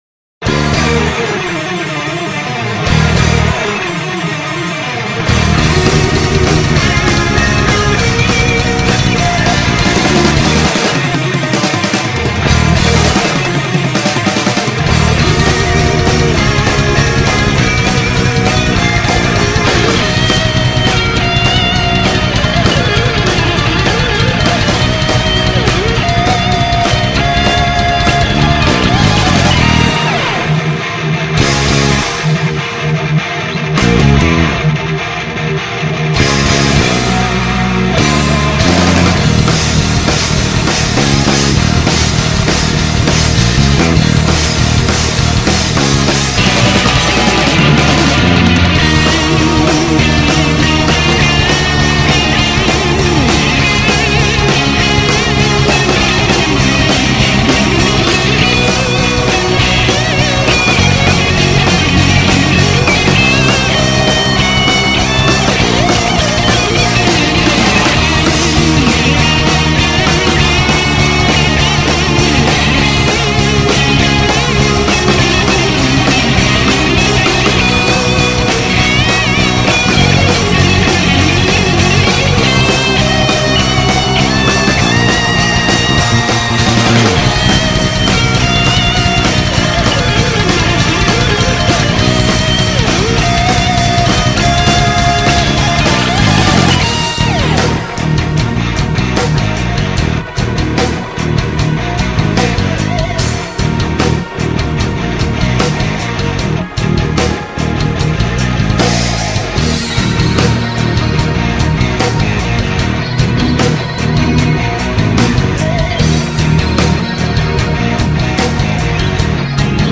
즉 실드럼은 잘 안친다는 얘기죠.
메탈 느낌이지만, 드럼이 실드럼이 아닌 이상 프로그래밍 적으로 되어있어 하드한 느낌은 적습니다.